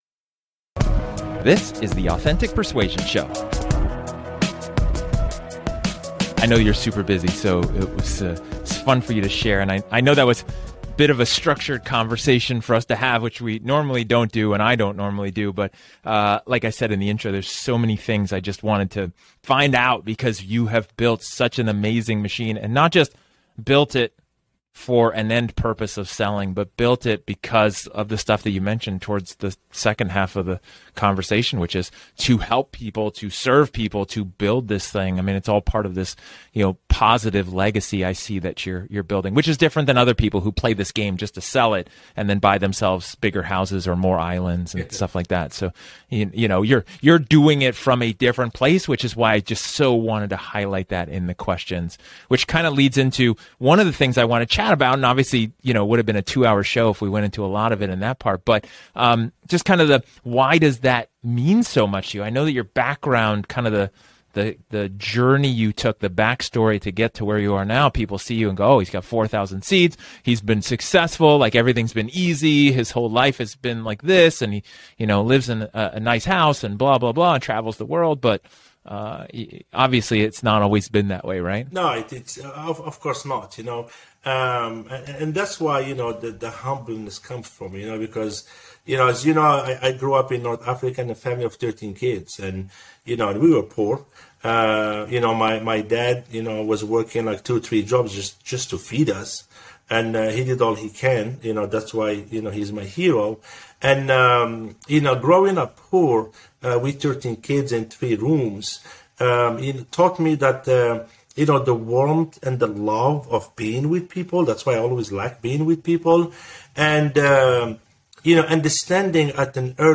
This is a casual conversation, off the cuff, and unscripted.